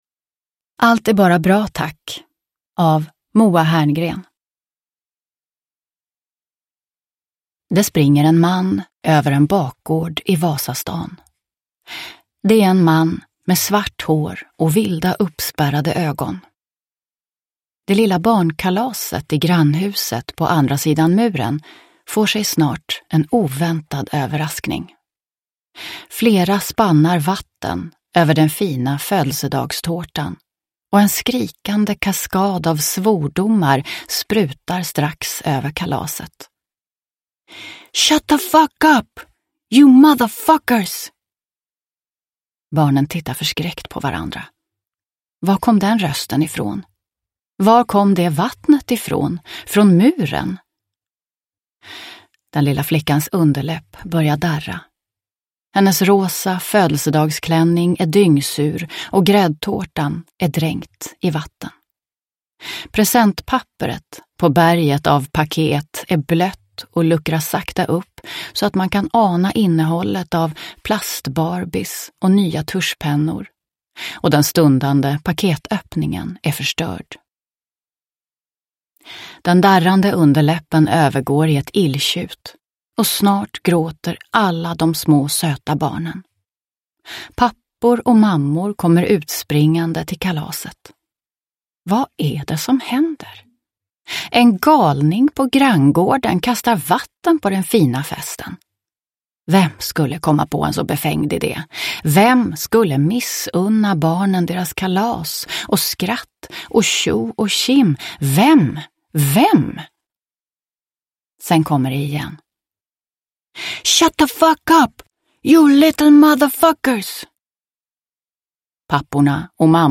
Allt är bara bra tack – Ljudbok – Laddas ner